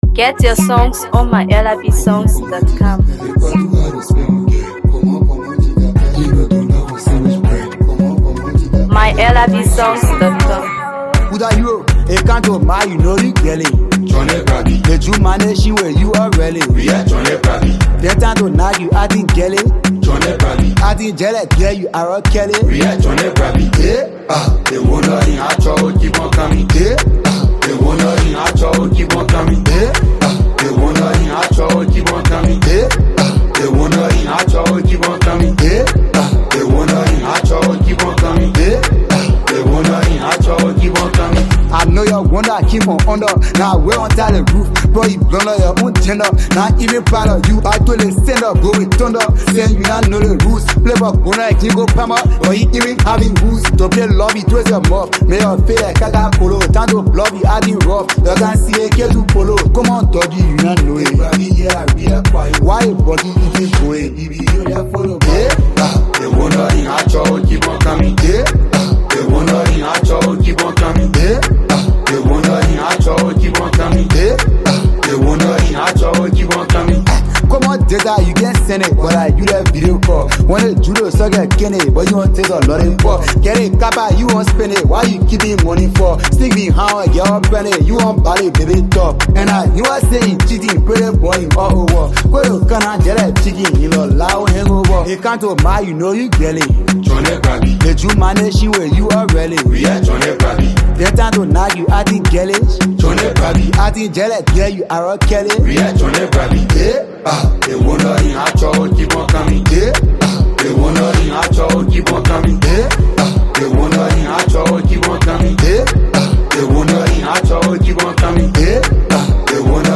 HipcoMusic
catchy rhythms with effortless delivery